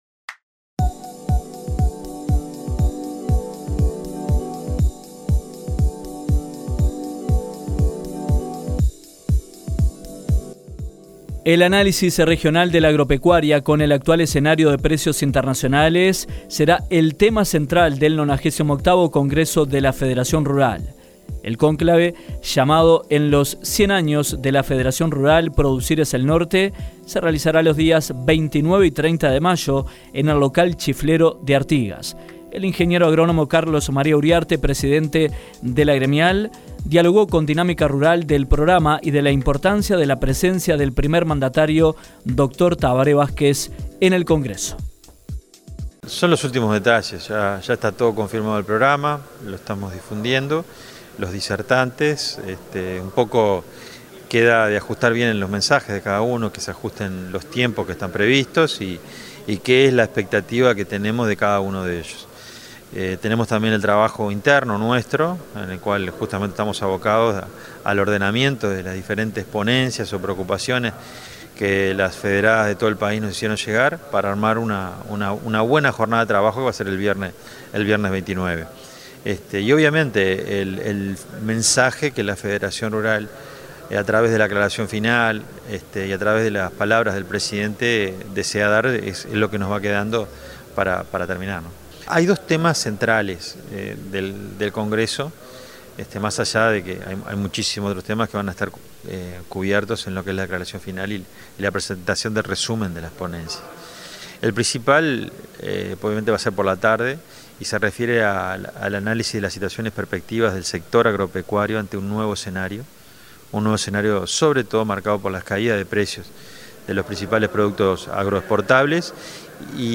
Carlos María Uriarte, presidente de la gremial, dialogó con Dinámica Rural del programa y de la importancia de la presencia del presidente de la República, Tabaré Vázquez, en la actividad.